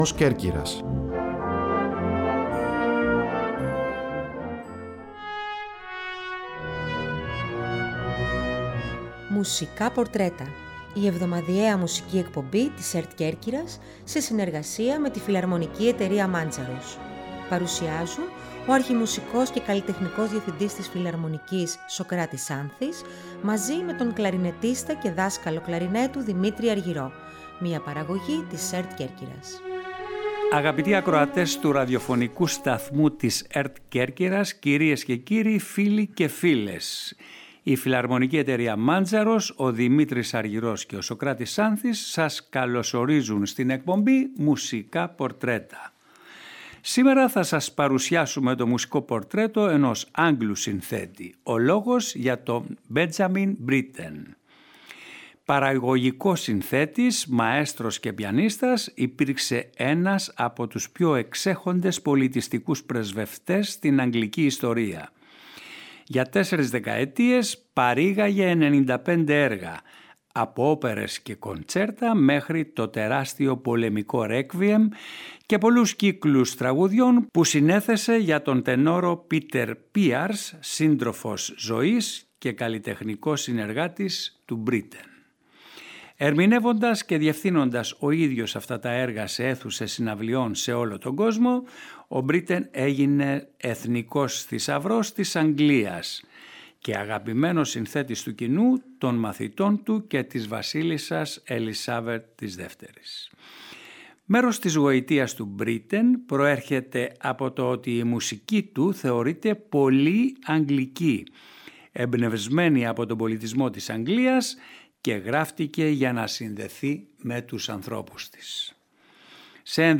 “Μουσικά Πορτρέτα” Εβδομαδιαία εκπομπή της ΕΡΤ Κέρκυρας σε συνεργασία με τη Φιλαρμονική Εταιρεία Μάντζαρος.